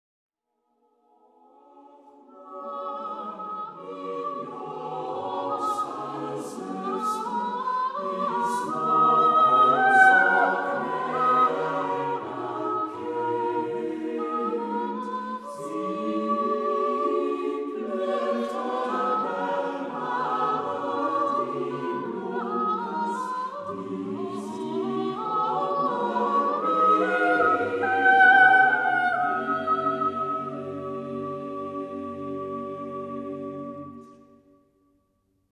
SATB (4 gemischter Chor Stimmen) ; Partitur.
Genre-Stil-Form: weltlich ; Volkstümlich ; Chorbearbeitung Charakter des Stückes: moralisierend ; traurig ; andante Chorgattung: SATB (4-stimmiger gemischter Chor )
Tonart(en): G-Dur